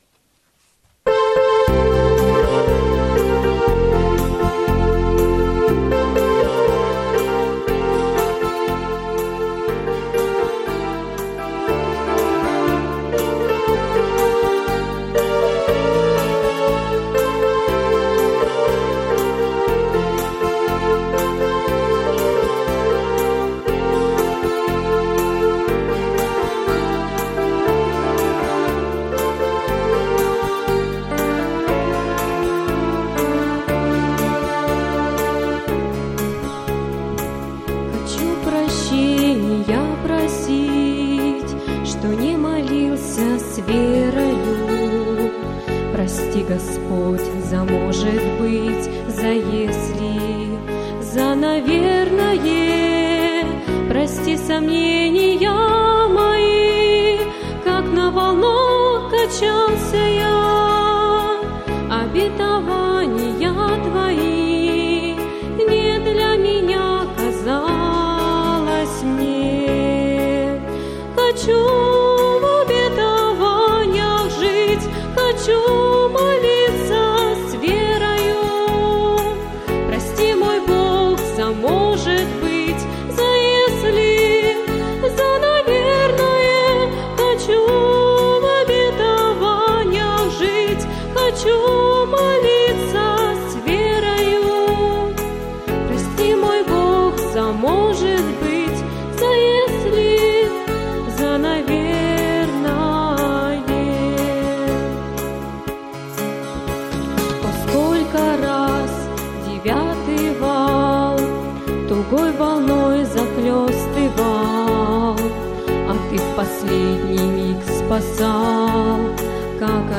Богослужение 12.09.2010 mp3 видео фото
Пение